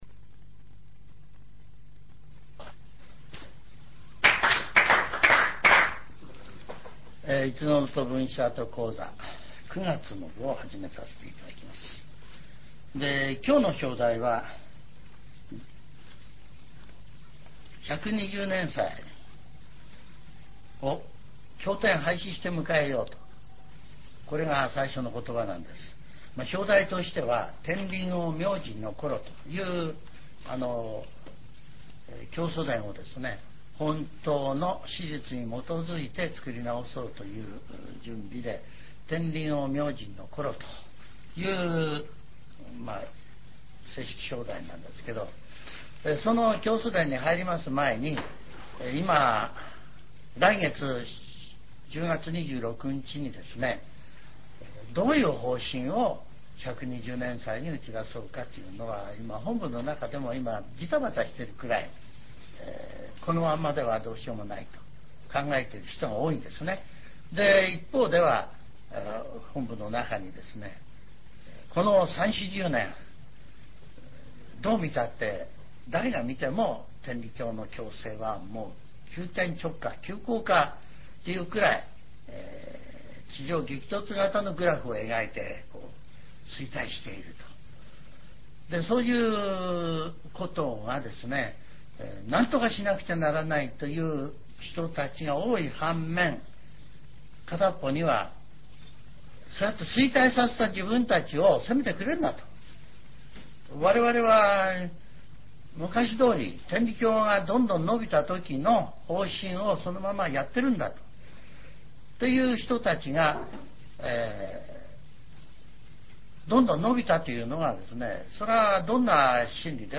ジャンル: Speech